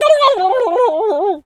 Animal_Impersonations
turkey_ostrich_hurt_gobble_05.wav